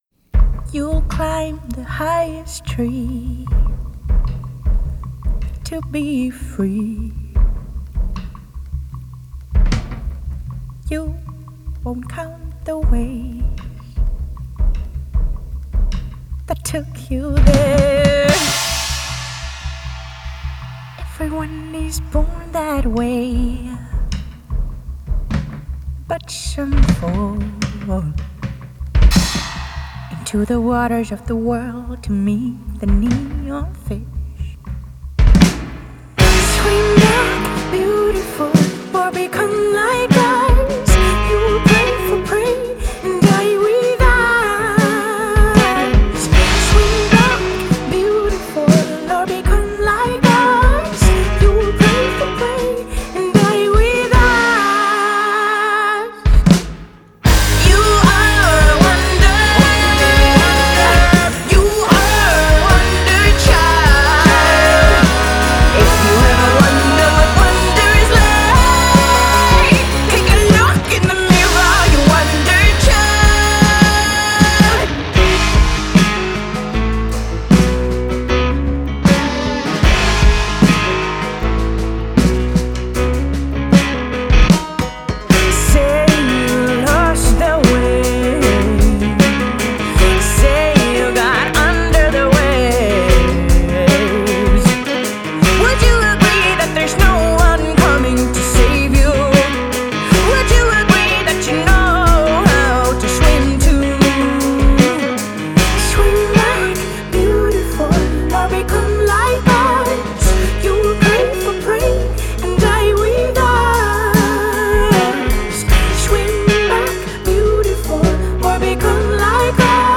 Genre: Indie Pop, Female Vocal, Experimental